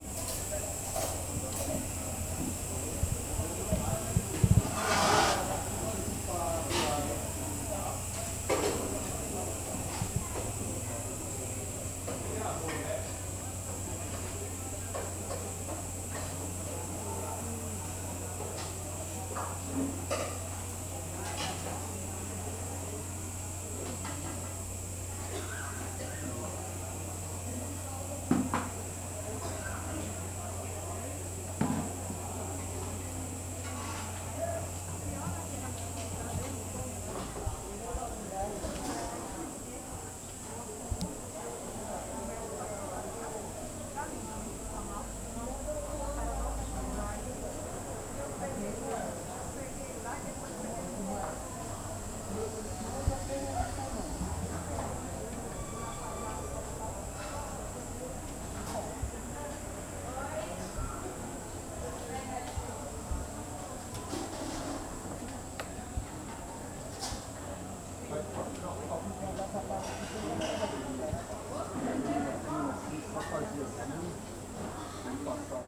Feira do Paranoa ao lado da Panela de Pressao e Restaurante sendo Montado Cozinha , Facas , Feira , Feira do Paranoá , Lanchonete , Panela de Pressao , Paranoá , Restaurantes , Vozerio
Surround 5.1
CSC-11-007-GV - Feira do Paranoa ao lado da Panela de Pressao e Restaurante sendo Montado.wav